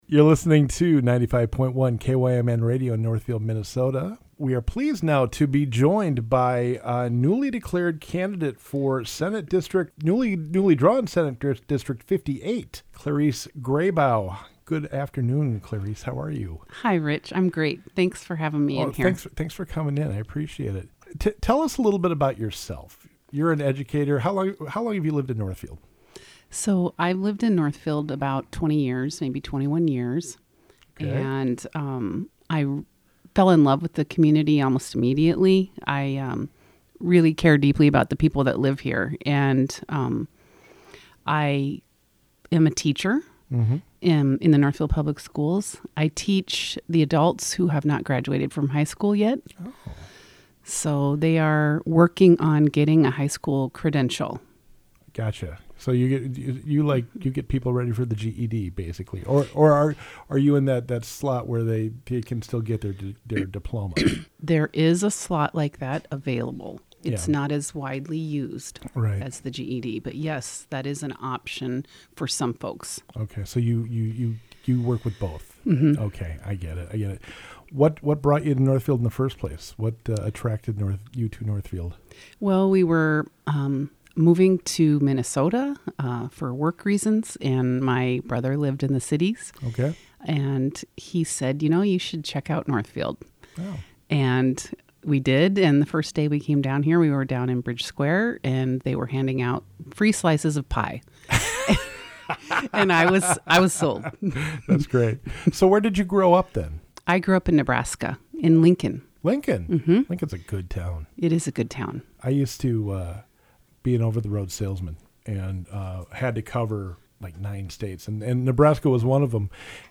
A conversation with Clarice Grabau, Candidate for Senate District 58